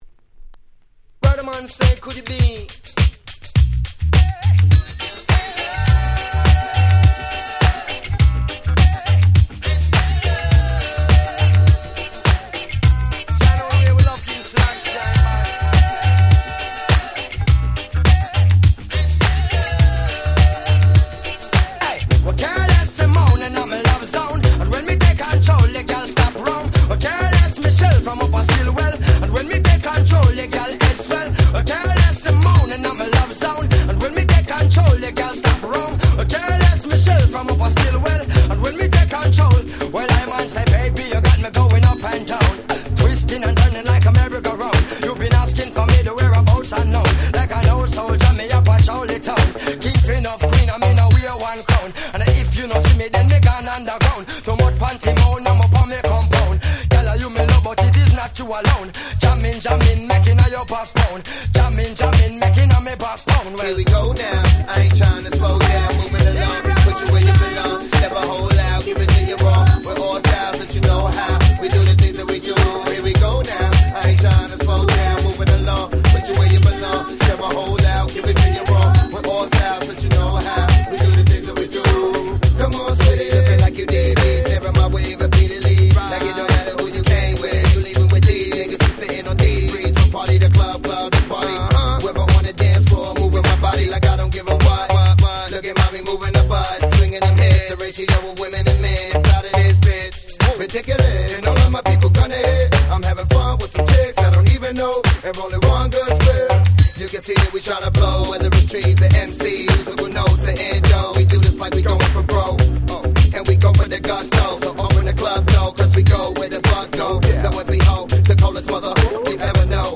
05' Super Hit Reggae !!